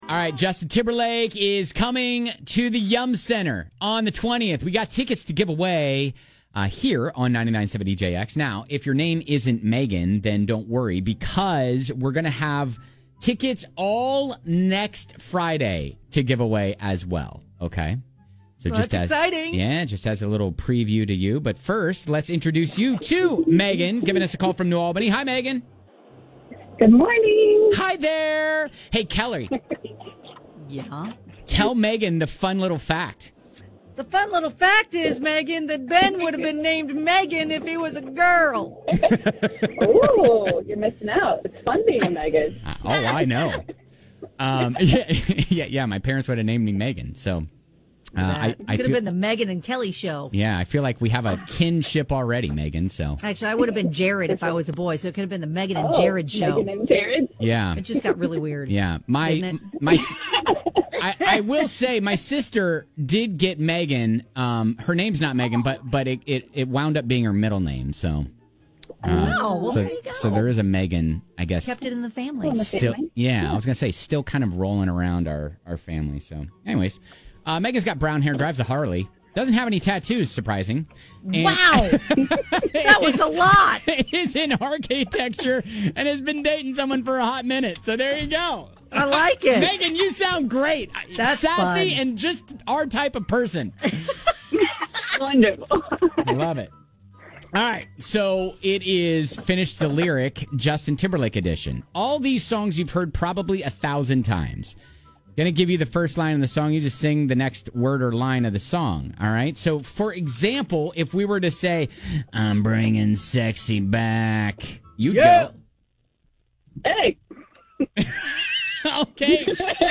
We’ll give you the 1st line of the song, you just have to sing the next word or line of the song!